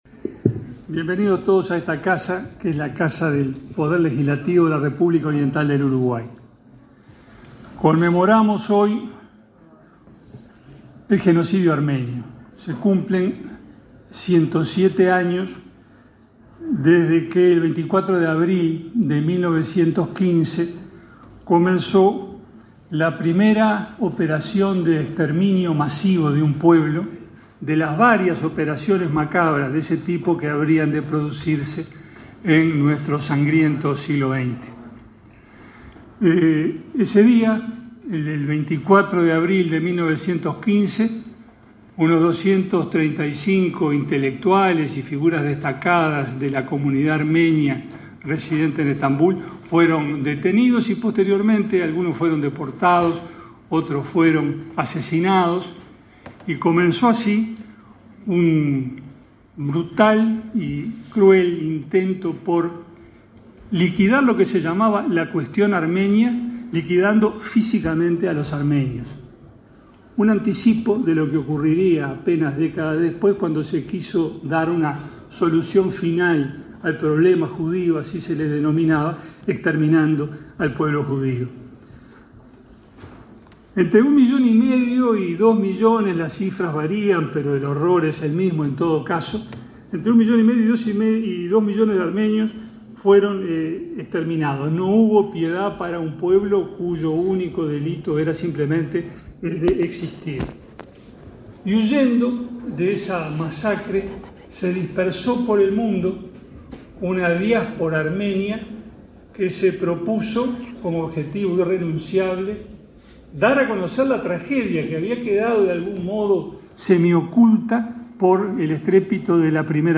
El presidente de la Cámara de Representantes, Ope Pasquet, y la vicepresidenta de la República, Beatriz Argimón, fueron oradores del acto